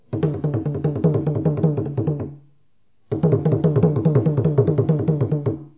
4.1.2.2. TRỐNG CƠM
Vê: bằng hai tay, hai âm.